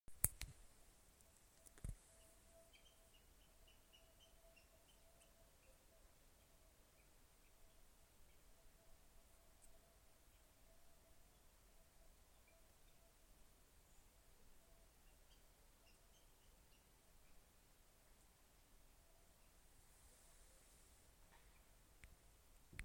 Water Rail, Rallus aquaticus
Ziņotāja saglabāts vietas nosaukumsPapes ezers, Putnu laipa
StatusVoice, calls heard